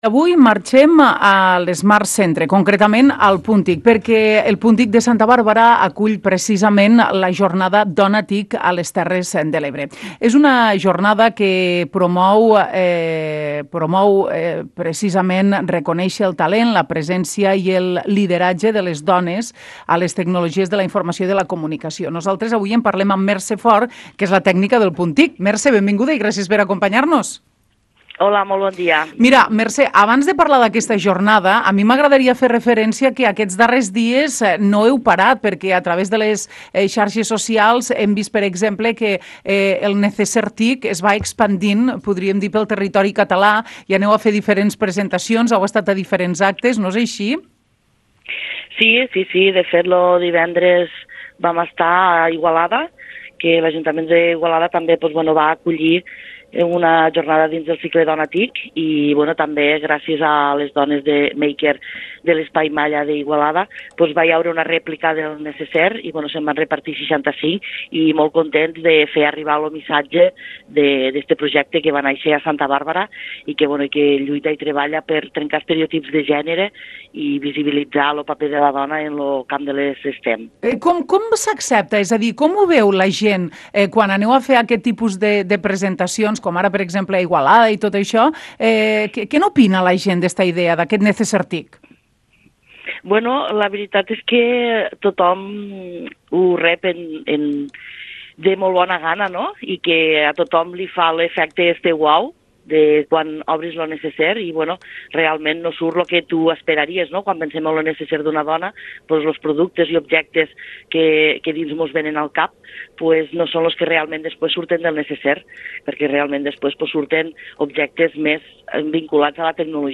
L'entrevista: La Jornada Dona TIC - La Plana Ràdio, 2024